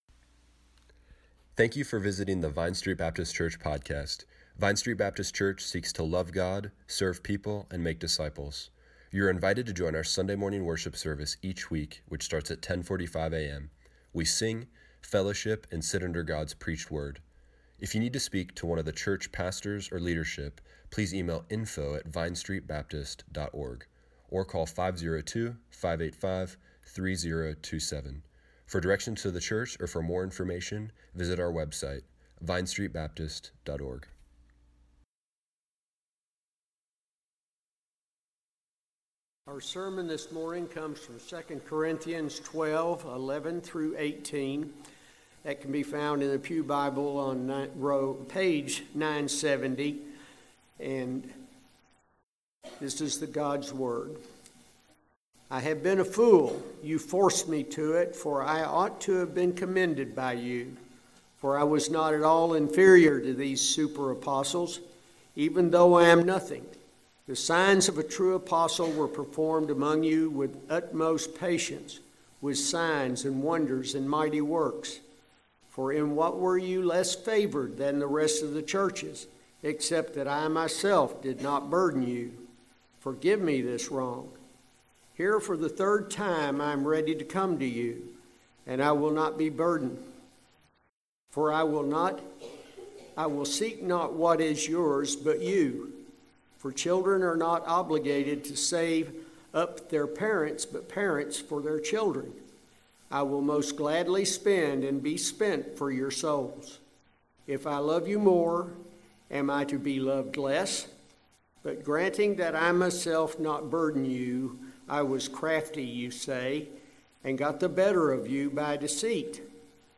October 15, 2017 Morning Worship | Vine Street Baptist Church